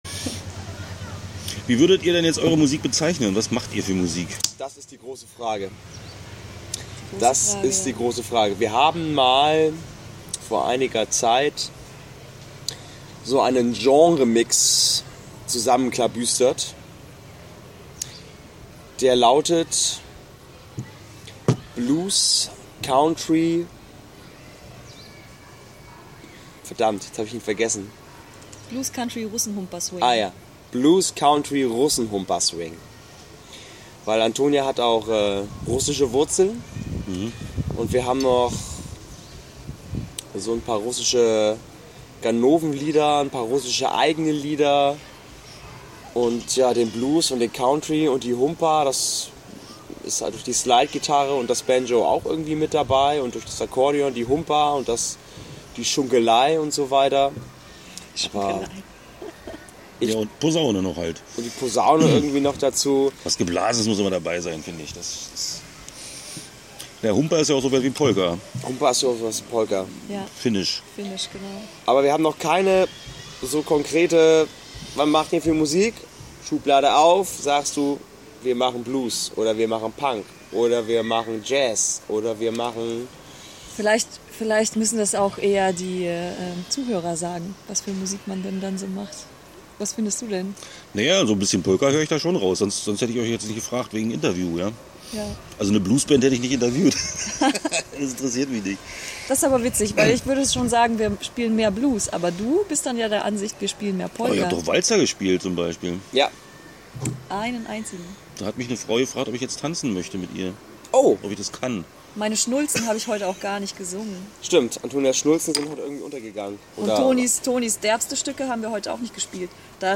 2014-09-20 Interview Teil I (8:55)